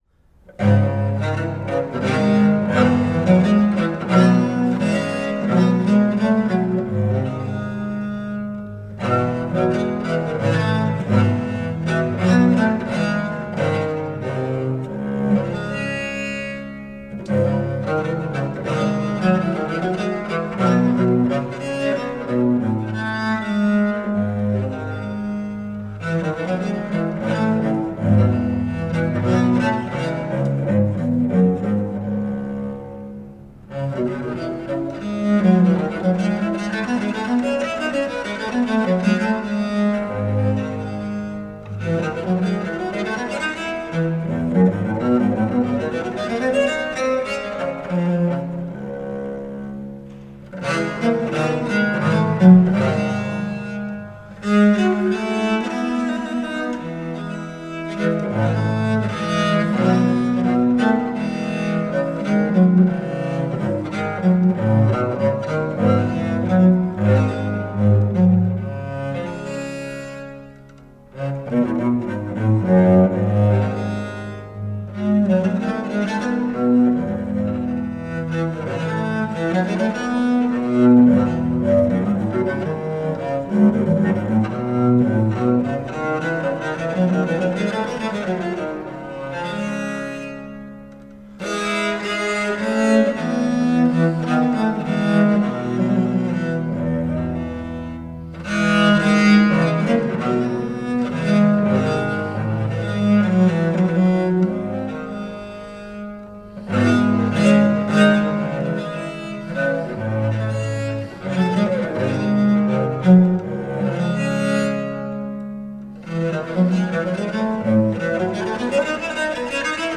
pièces pour viole seule extraites de l'album: "la voix de la viole" enregistré en juillet 2009 dans la chapelle de l'hôpital St-Louis à Paris: